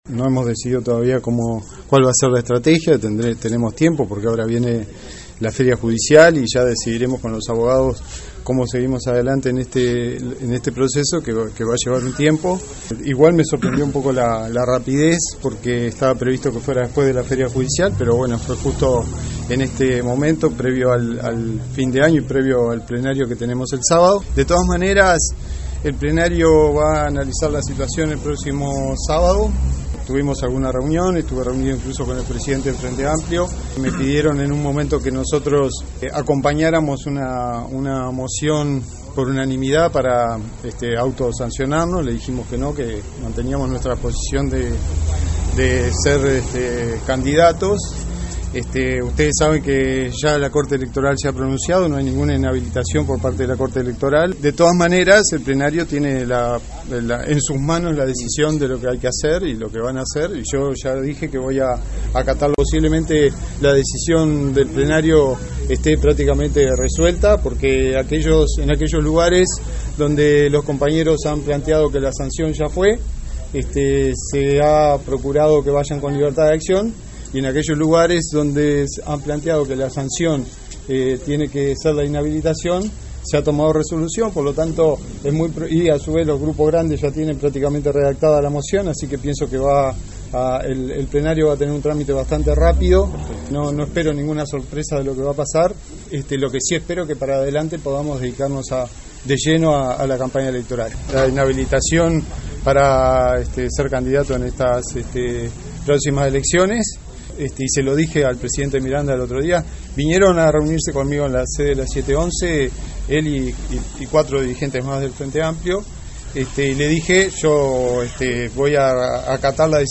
El ex vicepresidente Raúl Sendic dijo en rueda de prensa que mantuvo contactos con algunos referentes del partido antes del Plenario de mañana que abordará su caso. Indicó que rechazó apoyar una propuesta del presidente del Frente Amplio Javier Miranda para autosancionarse en el Plenario.